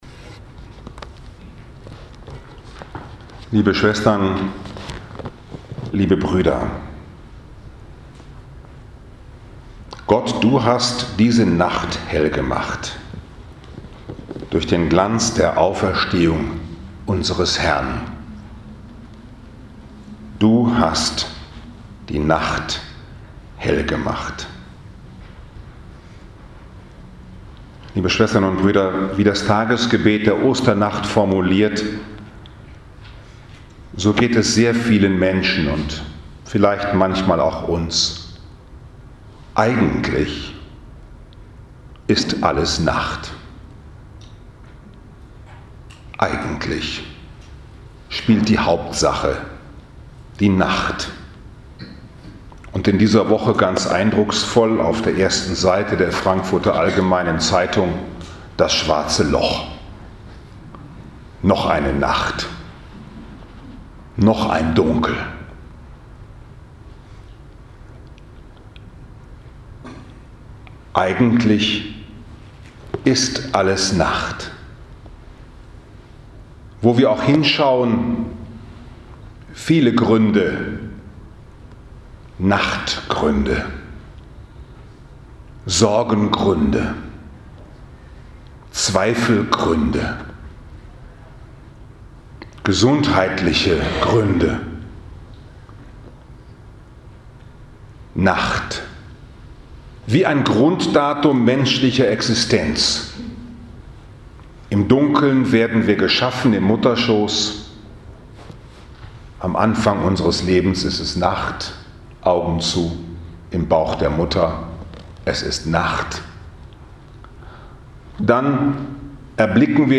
Ein Ursprung ins Leben Der Auferstandene lädt uns ein, sich ans Neue Leben zu binden und sich zu lösen von dem, was sowieso vergehen wird 20. April 2019, 20.30 Uhr Kapelle Franziska-Schervier-Heim, Feier der Osternacht